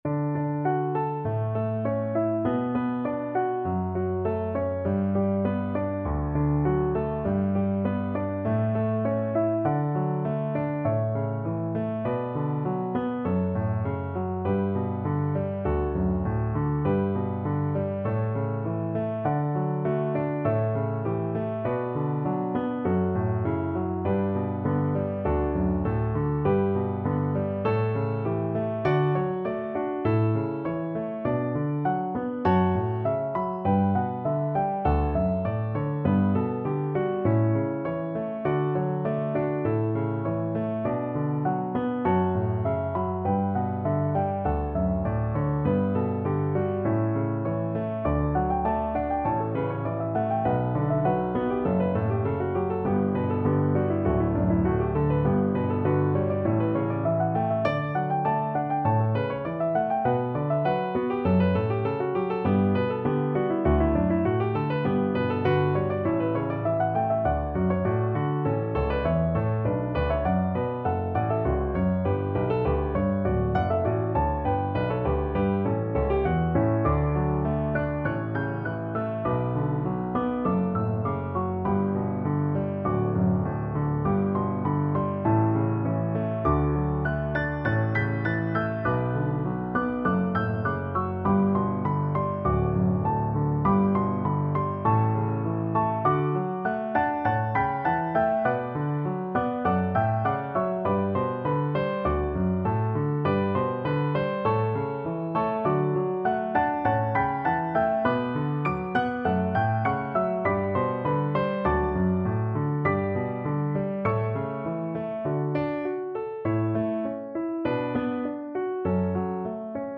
Classical Pachelbel, Johann Canon in D Piano Four Hands (Piano Duet) version
Wedding Wedding Piano Four Hands (Piano Duet) Sheet Music Canon in D
Free Sheet music for Piano Four Hands (Piano Duet)
4/4 (View more 4/4 Music)